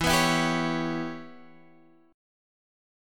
Em6 Chord
Listen to Em6 strummed